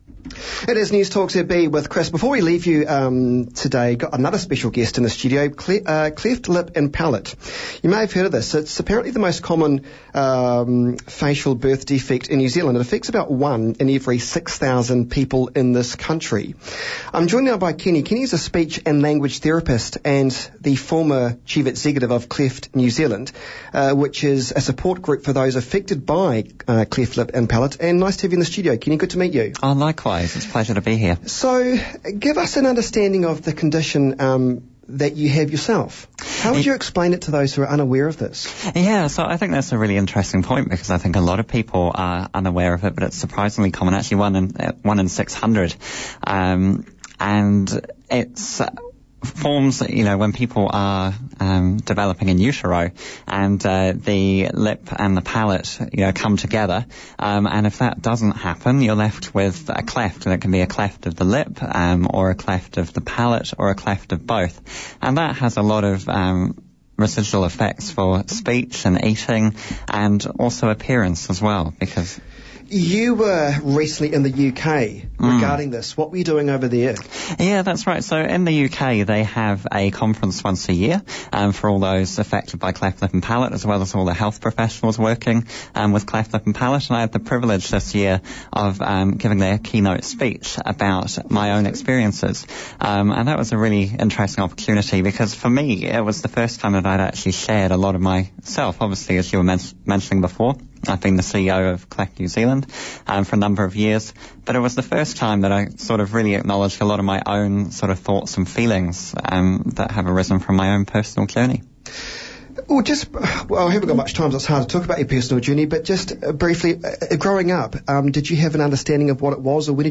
Interview on Newstalk ZB Christchurch, 19 Oct 2017
newstalk-zb-interview.mp3